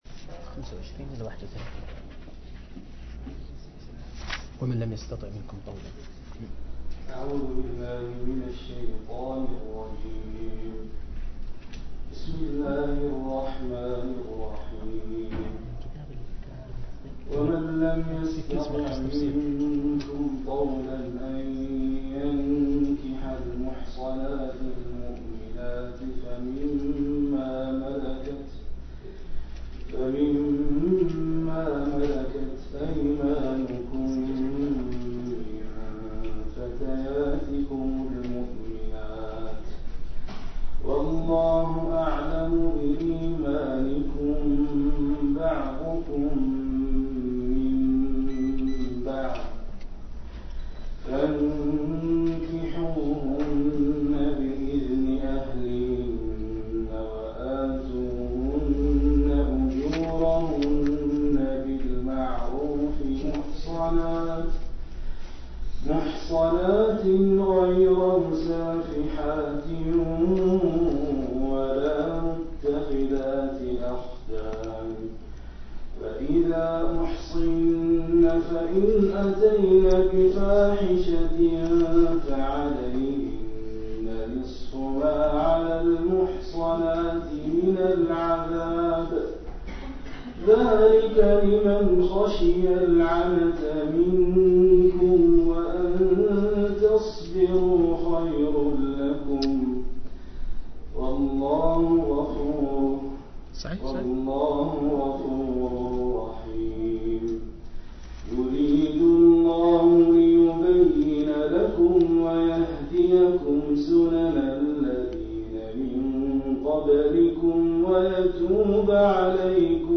086- عمدة التفسير عن الحافظ ابن كثير رحمه الله للعلامة أحمد شاكر رحمه الله – قراءة وتعليق –